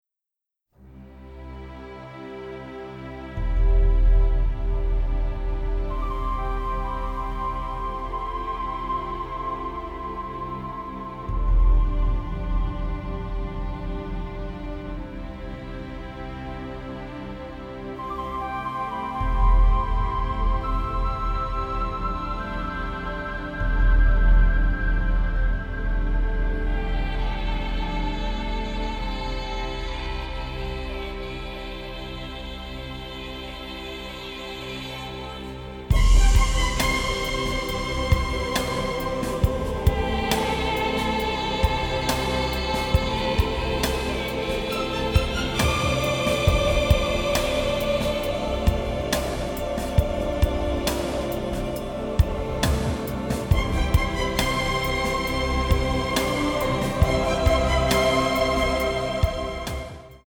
adventure score
Middle Eastern flavor and religious splendor